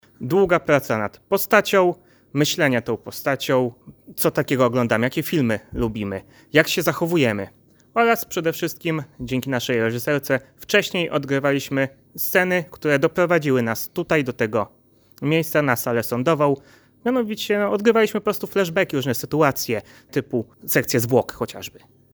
Nasz reporter rozmawiał